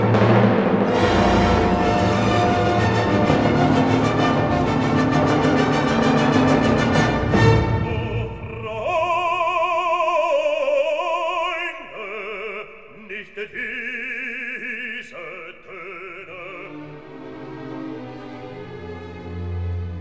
Negli esempi seguenti è possibile ascoltare un frammento della 9a registrato a frequenza di campionamento ottimale,